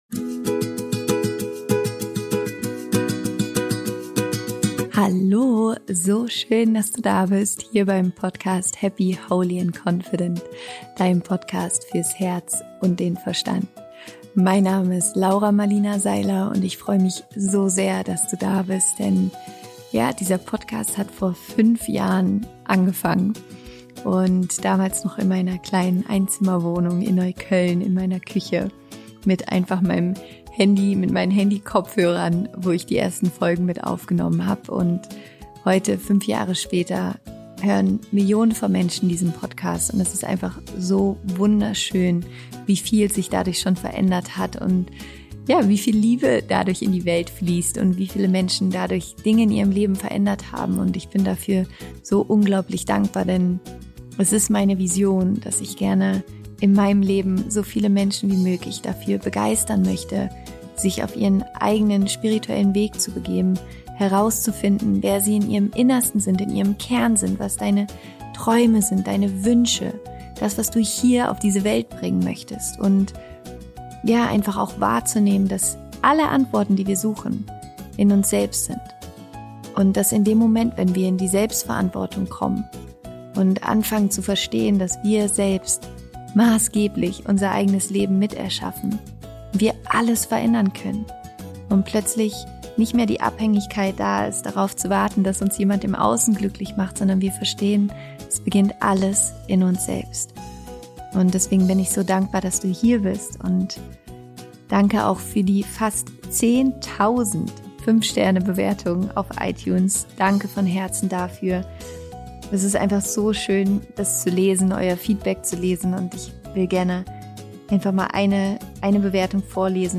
Vor knapp 2 Wochen fand unser mega tolles happy, holy & confident Celebration Event statt. Dort durfte ich den wundervollen Biyon Kattilathu auf der Bühne interviewen und ich freue mich riesig, heute das Interview mit dir zu teilen.